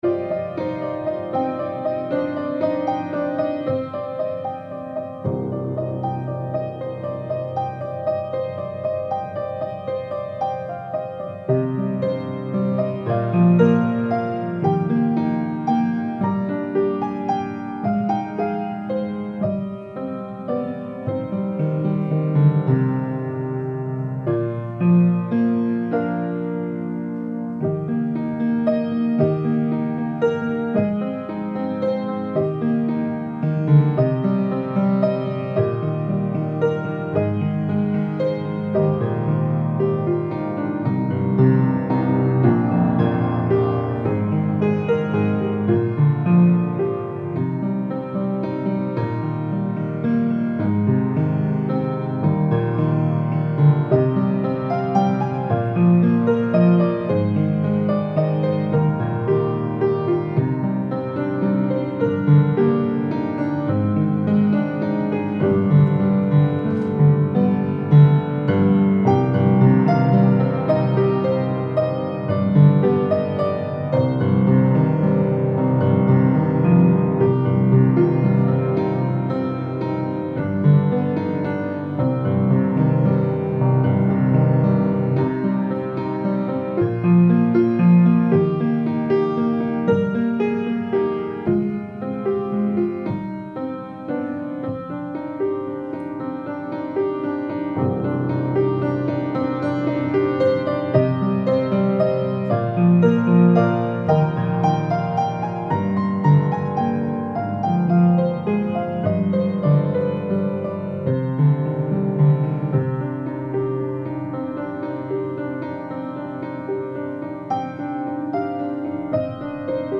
Christmas Eve Candlelight Service 2023